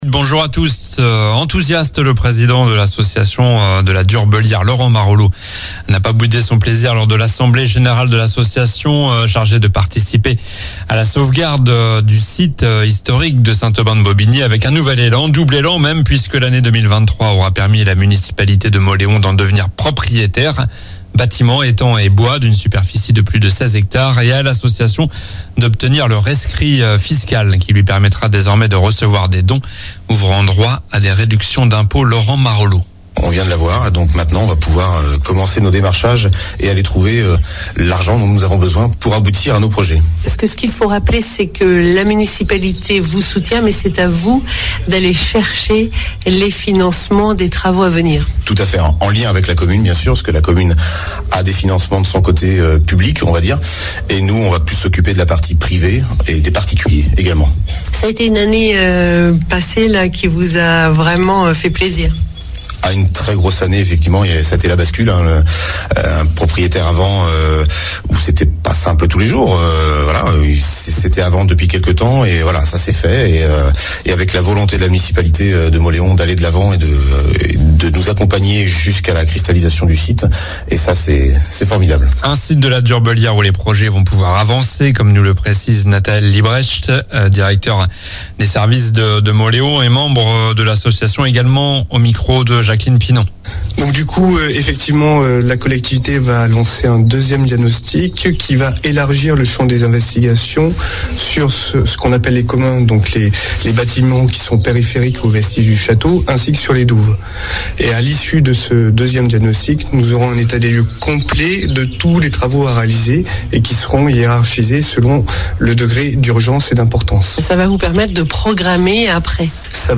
Journal du samedi 20 avril (matin)